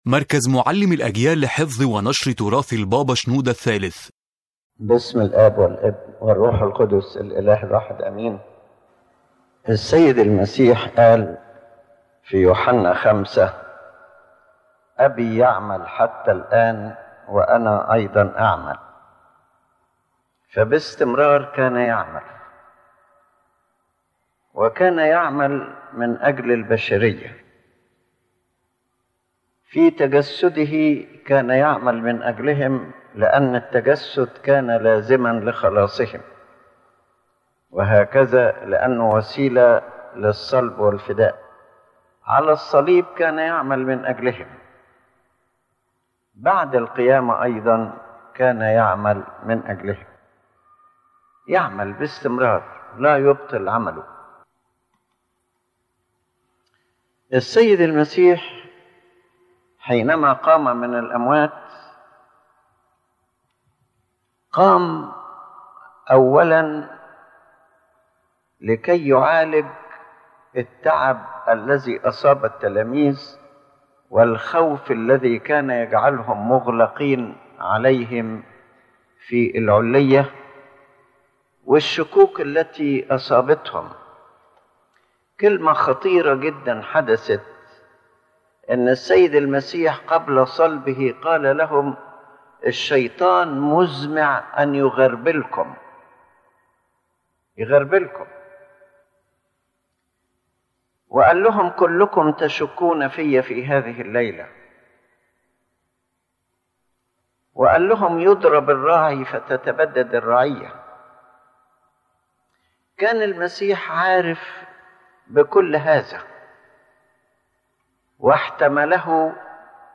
The lecture explains that the work of Christ did not stop at the Cross or the Resurrection, but continued after the Resurrection in caring for His disciples, healing their weakness, strengthening their faith, and preparing them to carry the mission of the Church to the whole world.